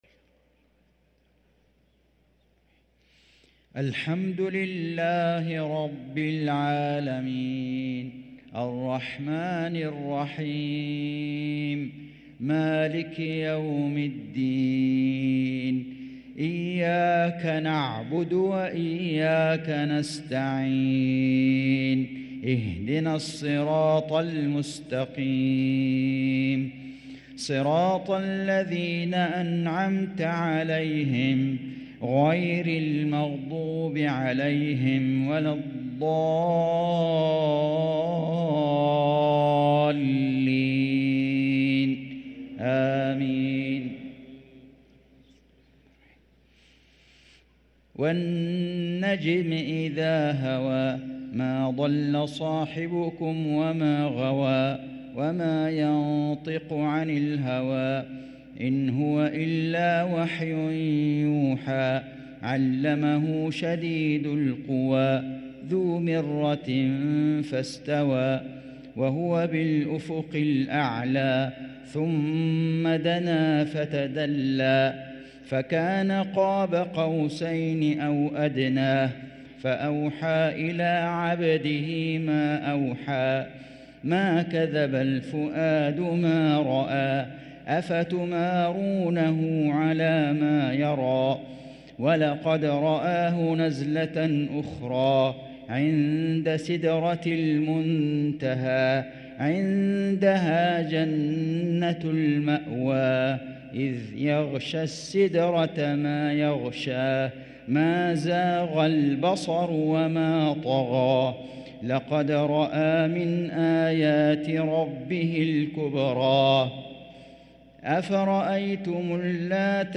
صلاة العشاء للقارئ فيصل غزاوي 24 شعبان 1444 هـ
تِلَاوَات الْحَرَمَيْن .